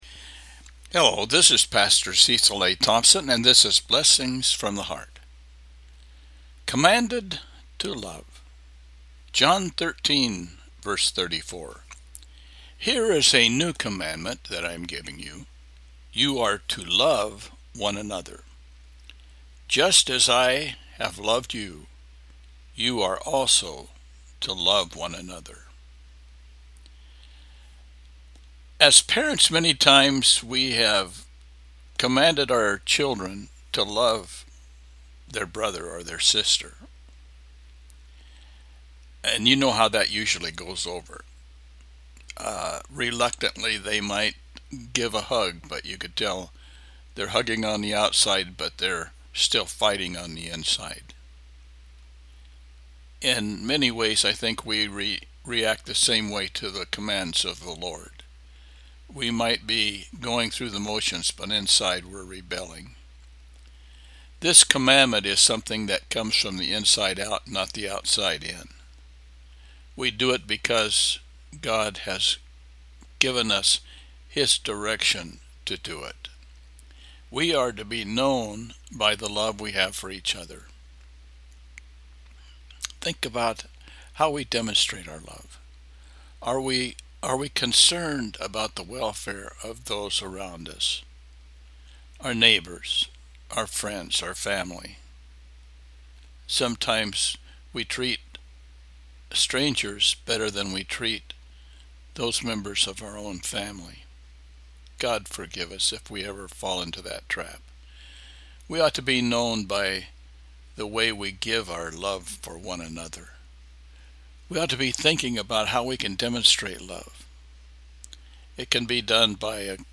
John 13:34 – Devotional